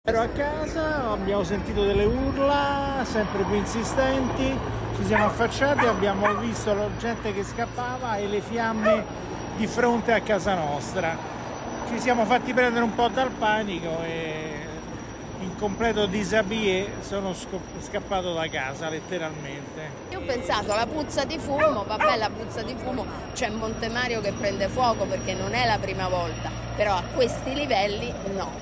Ecco alcune testimonianze
TESTIMONIANZE-ROMA-1-GR-1930.mp3